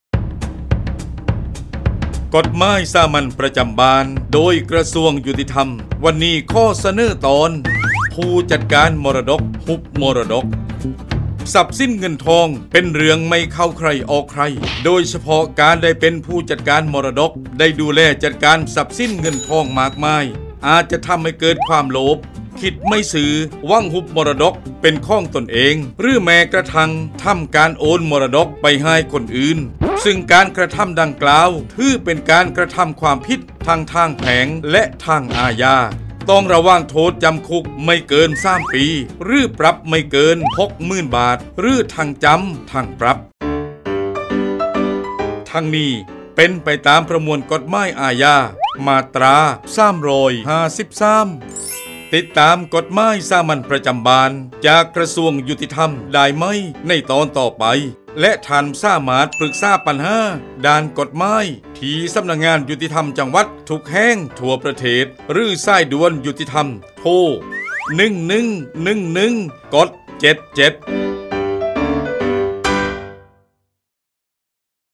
กฎหมายสามัญประจำบ้าน ฉบับภาษาท้องถิ่น ภาคใต้ ตอนผู้จัดการมรดก ฮุบมรดก
ลักษณะของสื่อ :   บรรยาย, คลิปเสียง